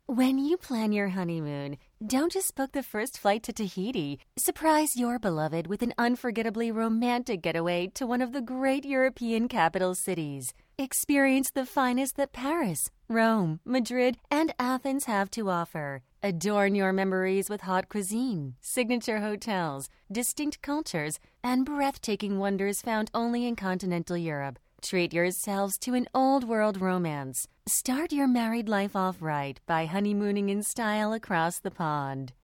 Female
Radio Commercials
Smooth, Luxurious And Engaging